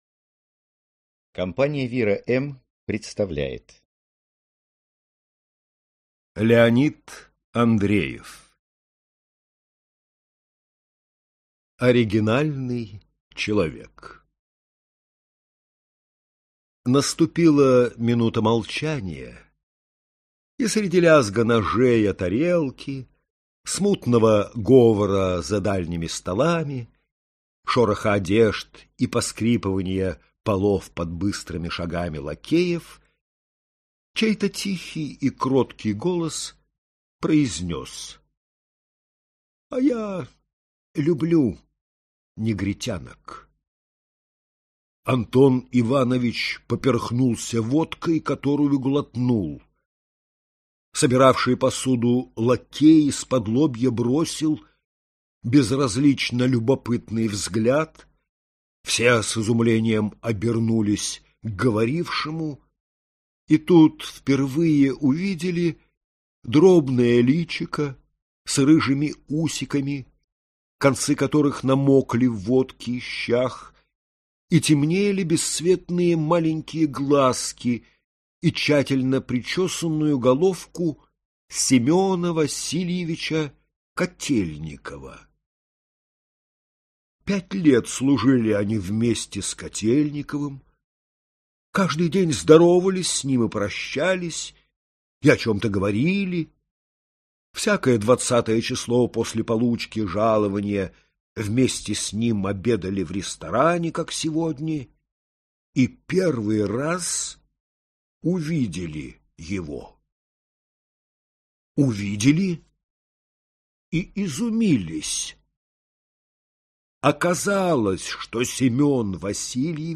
Аудиокнига Оригинальный человек. Рассказы | Библиотека аудиокниг